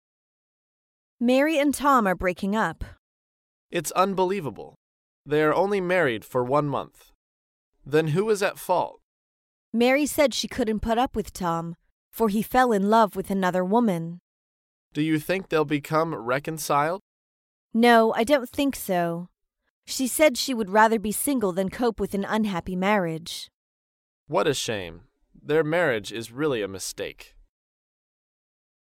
在线英语听力室高频英语口语对话 第179期:夫妻离婚的听力文件下载,《高频英语口语对话》栏目包含了日常生活中经常使用的英语情景对话，是学习英语口语，能够帮助英语爱好者在听英语对话的过程中，积累英语口语习语知识，提高英语听说水平，并通过栏目中的中英文字幕和音频MP3文件，提高英语语感。